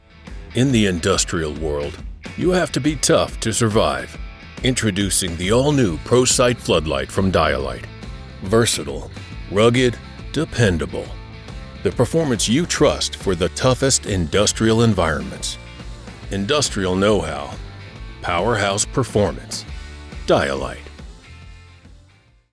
Voiceover
Product Promo